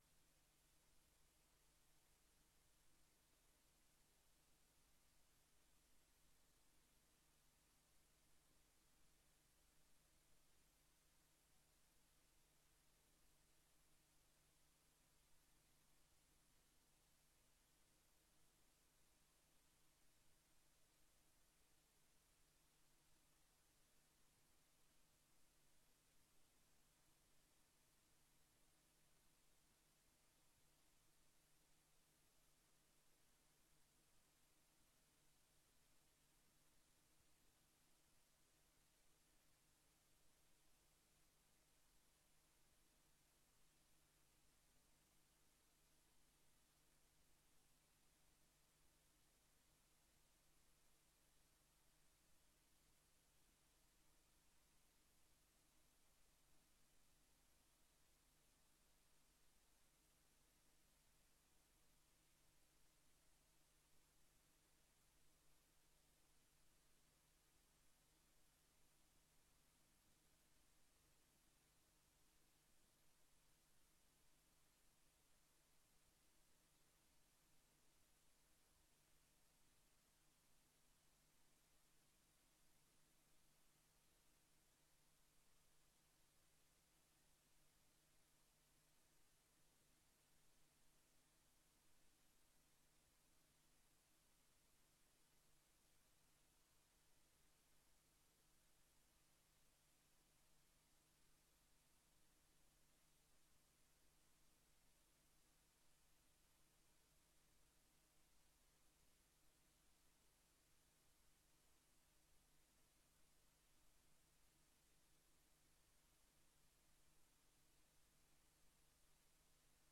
Raadsvergadering 07 november 2024 20:00:00, Gemeente Leusden
Locatie: Raadzaal